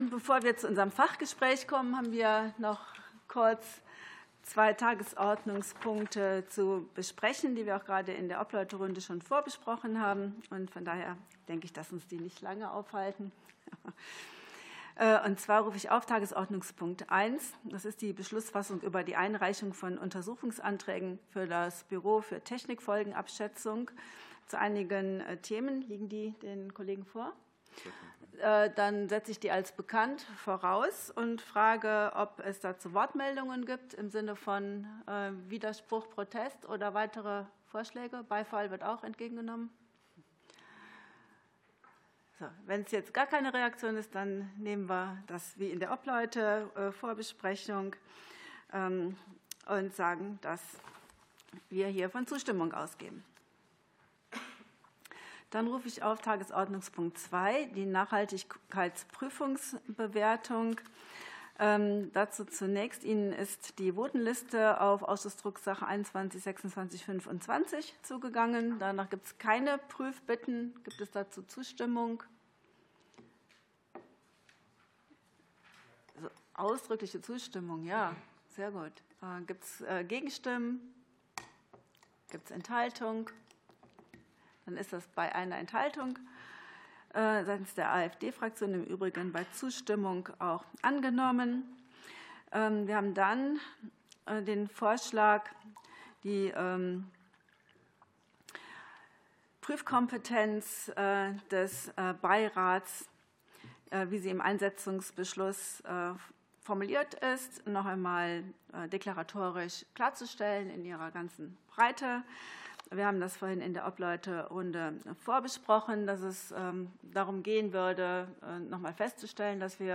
Öffentliches Fachgespräch zum Thema "Nachhaltige Ernährung" ~ Ausschusssitzungen - Audio Podcasts Podcast